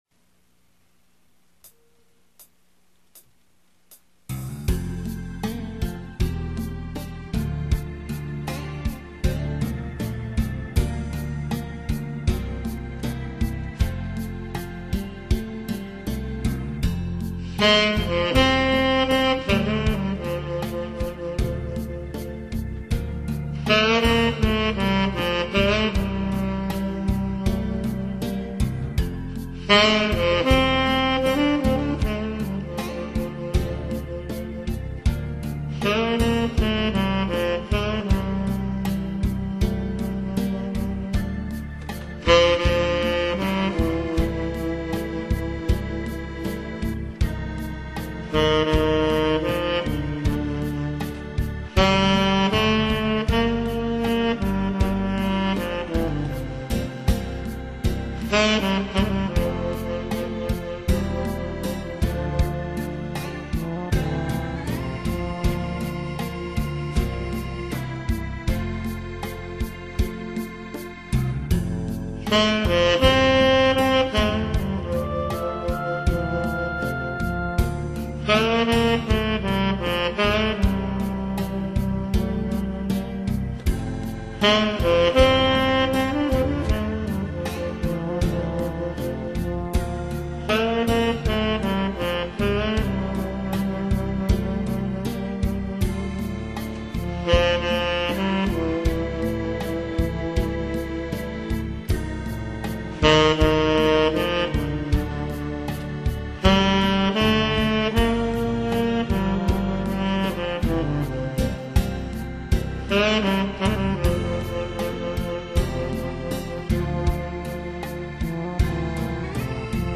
아마추어의 색소폰 연주